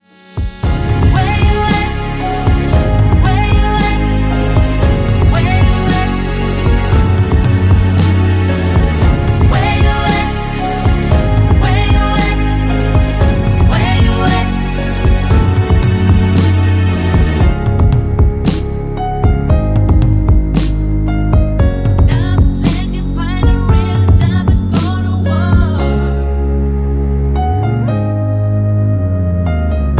Bb
Backing track Karaoke
Pop, Musical/Film/TV, 2000s